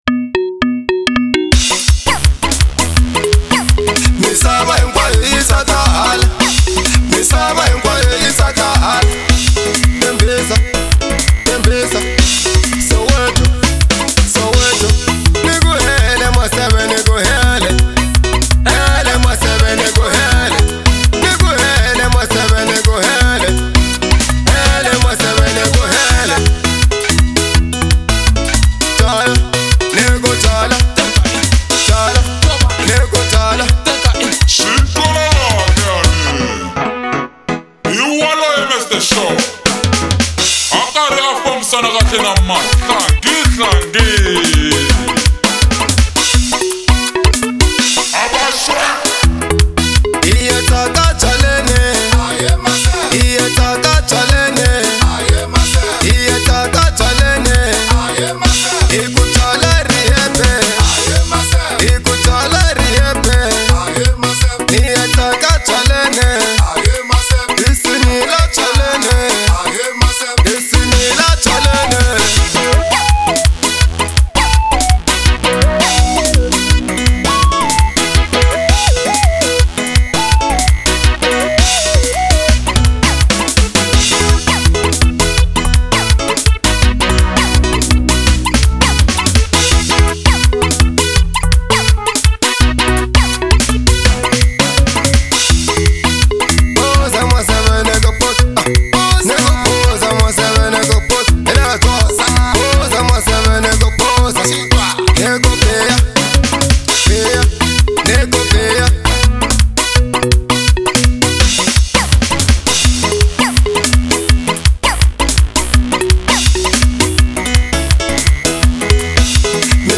04:31 Genre : Xitsonga Size